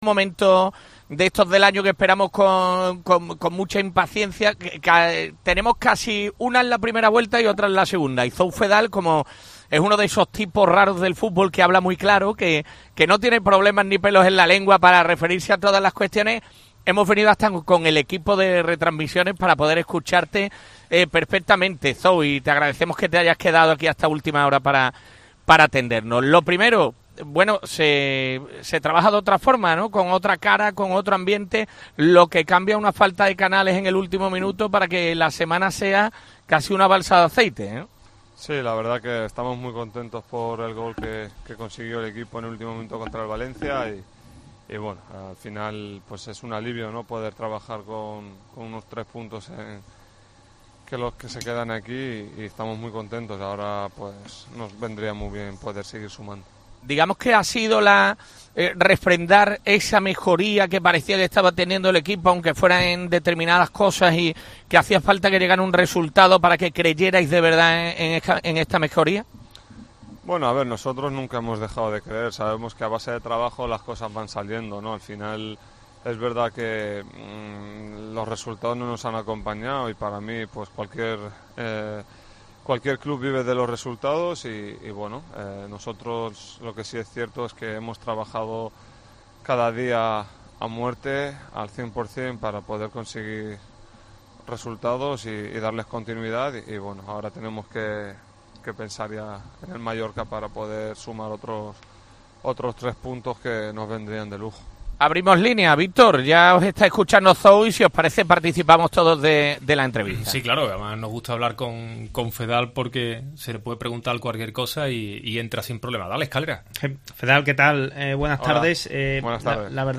Zou Feddal, en la entrevista de Cope Más Sevilla de este jueves